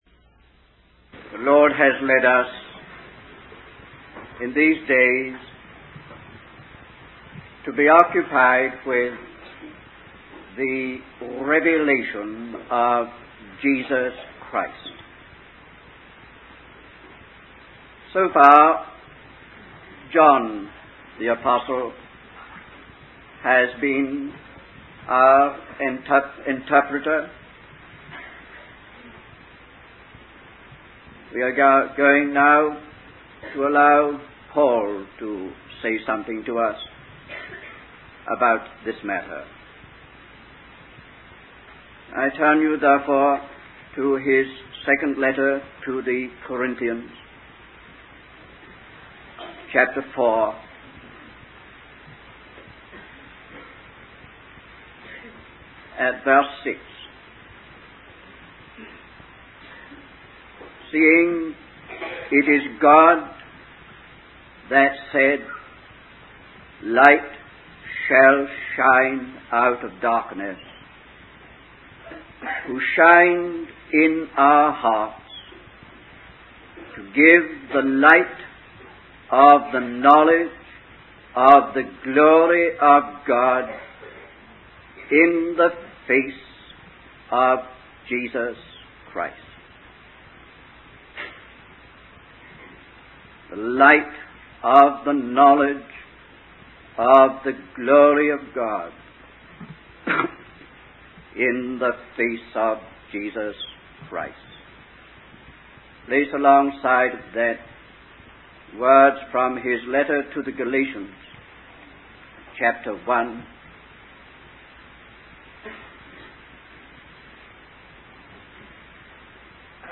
In this sermon, the speaker discusses the fall of man and the earth after Adam disobeyed God.